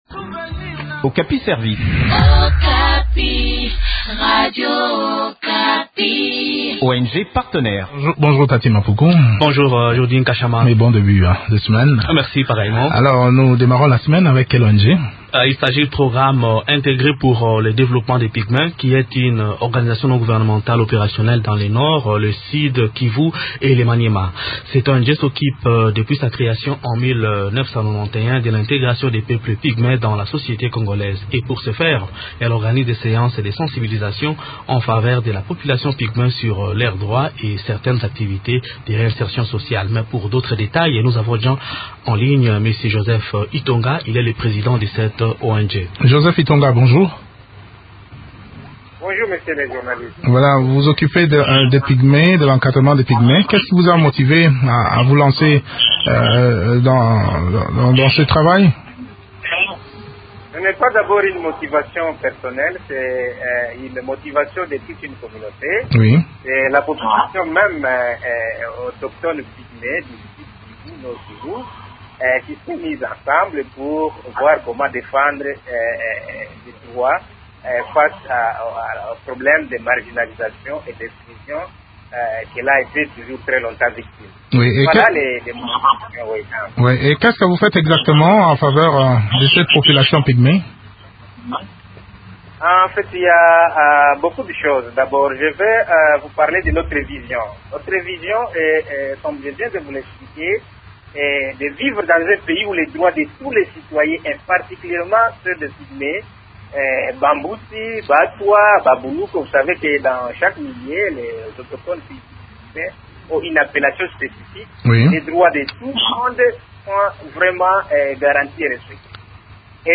Découvrons les activités de cette ONG dans cette interview